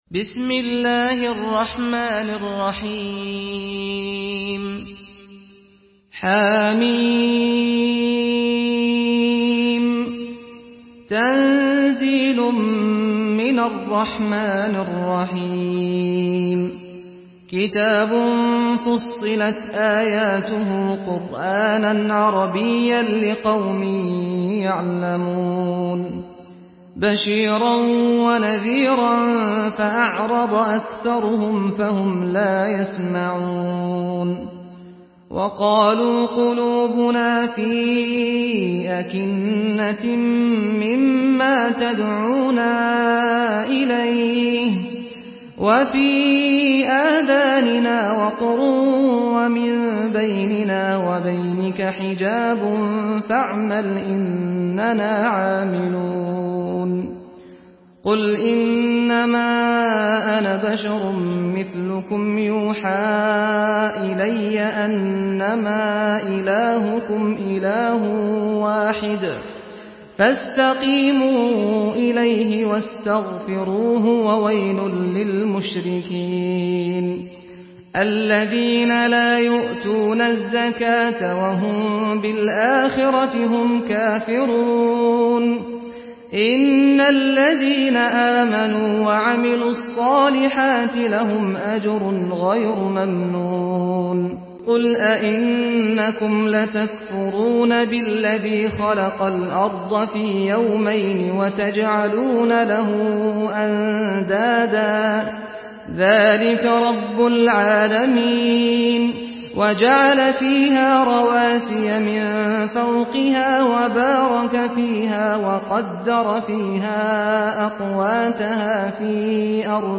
Surah Fussilet MP3 by Saad Al-Ghamdi in Hafs An Asim narration.
Murattal Hafs An Asim